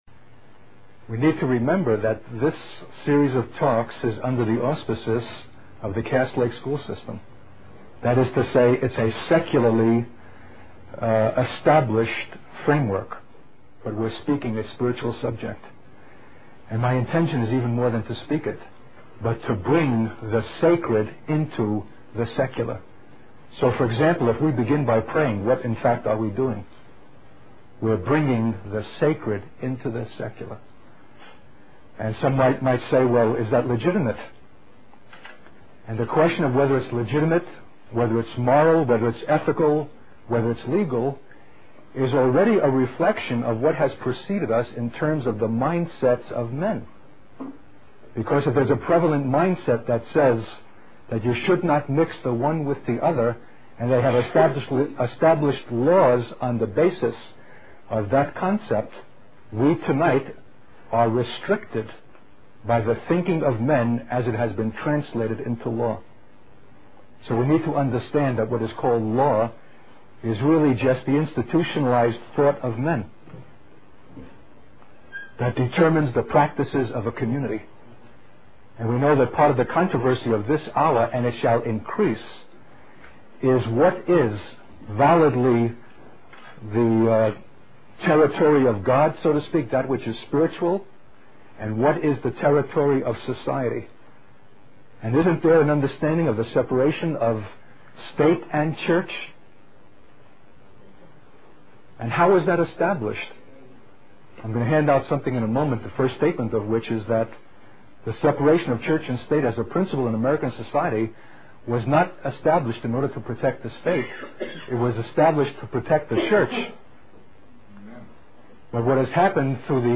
In this sermon, the speaker discusses the importance of recognizing the existence of a real enemy, especially after crossing over from death to life through faith in Jesus.